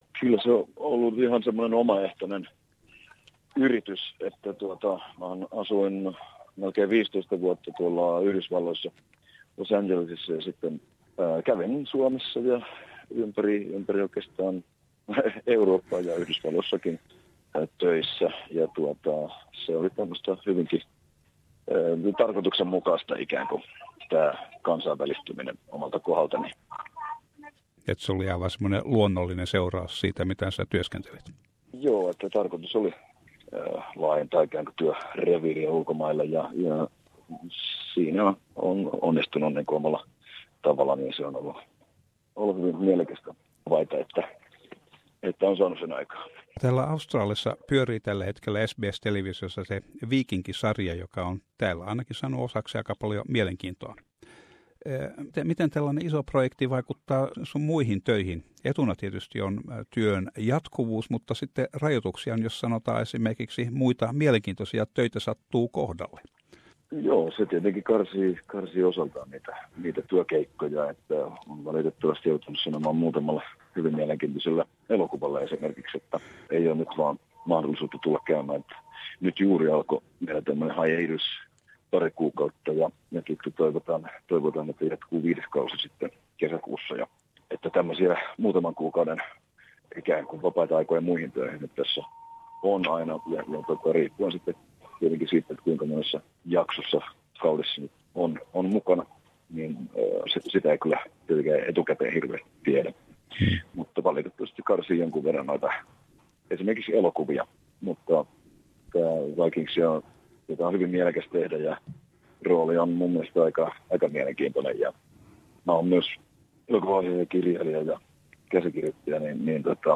Peter Franzen haastattelu